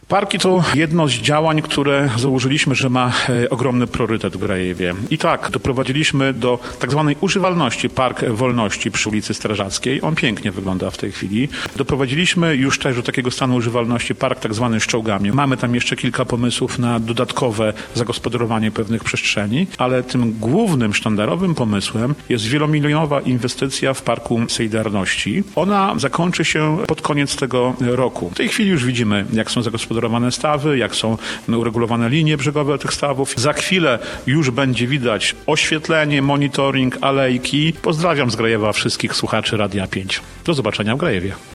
-Postawiliśmy na inwestycję w parki – mówi Dariusz Latarowski, burmistrz Grajewa.